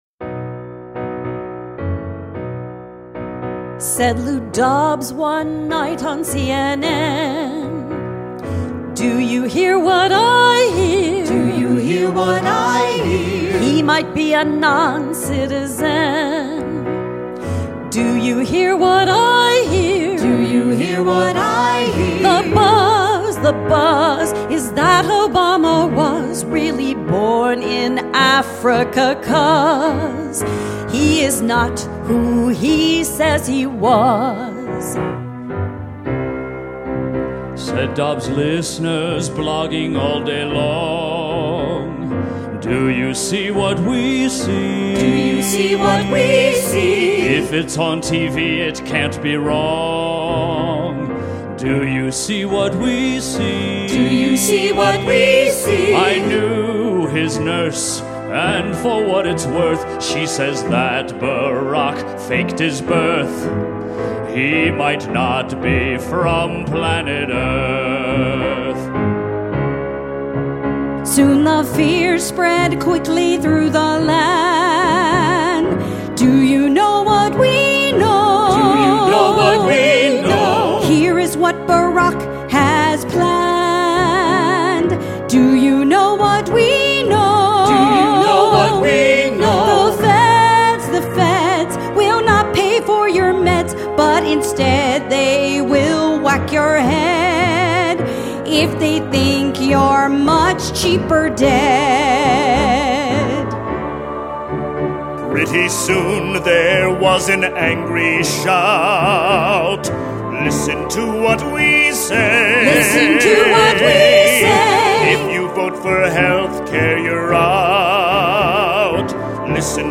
A satirical Holiday piece